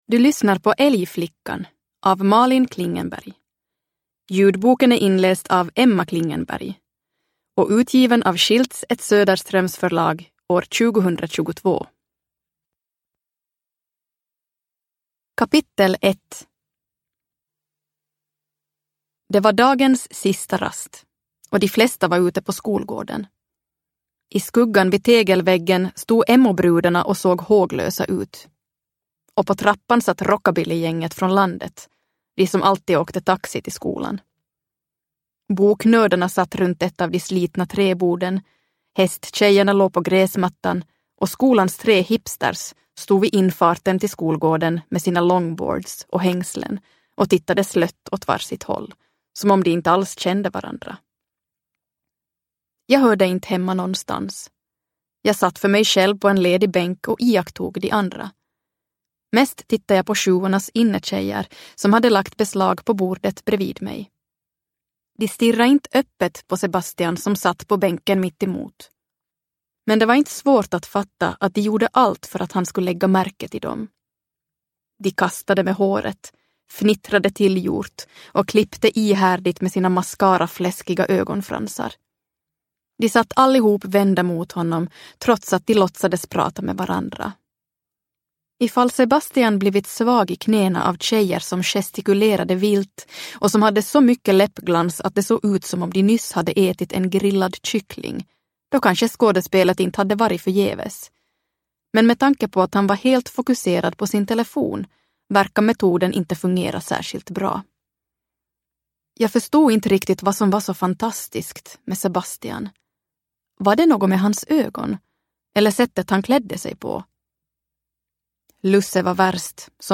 Älgflickan – Ljudbok – Laddas ner